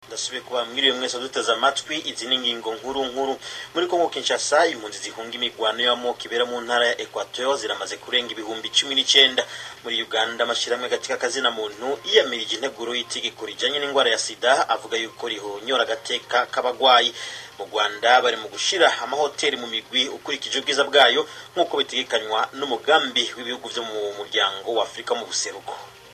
The announcer says “Équateur” in French (the name of the Congolese province bordering on Rwanda and Burundi), also Kinshasa with a French pronunciation.